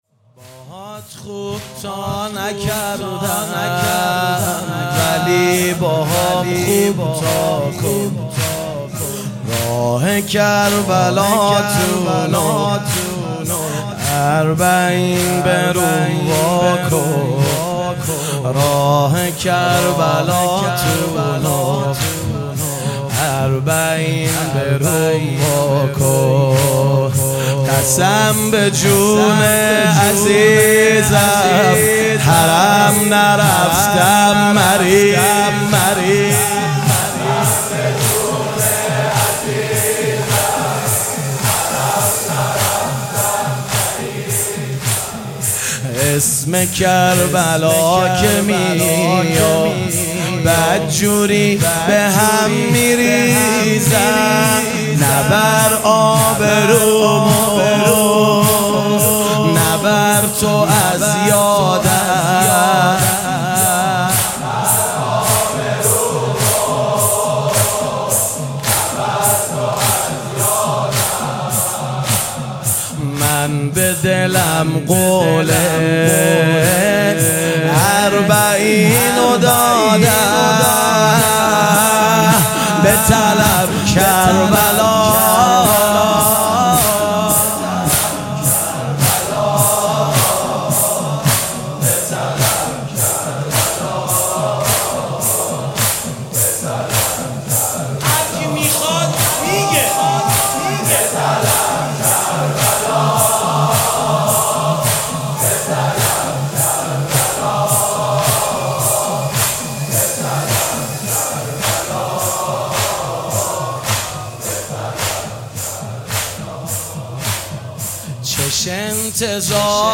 فایل صوتی|قسم به جون عزیزم ، حرم نرفتم مریضم|زمینه|اربعین حسینی1402 - هیئت حیدریون اصفهان
آستانه امامزاده محسن